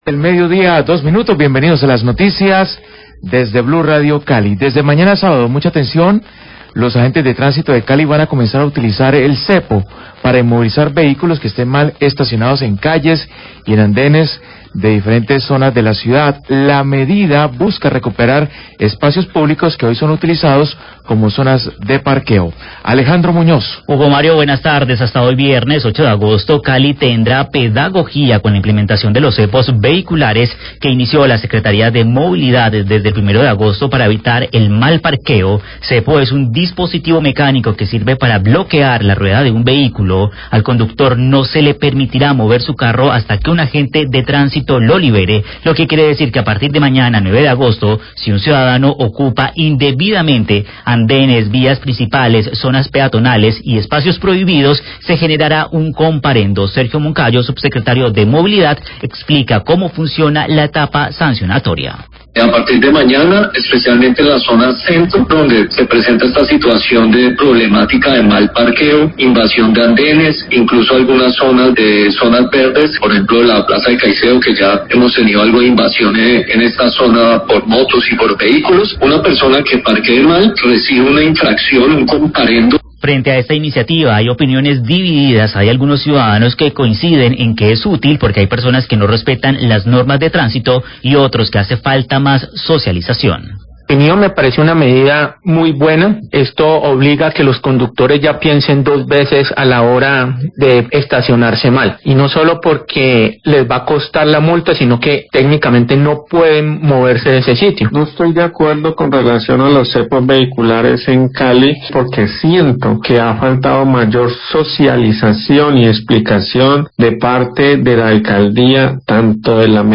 Radio
El subsecretario de movilidad Sergio Moncayo anuncia el inicio delo uso de cepos como medida sancionatoria y pedagógica para conductores de vehículos mal parqueados. El vehículo será liberado una vez el agente llegue a imponer el comparendo. Hablan ciudadanos a favor y en contra de la medida.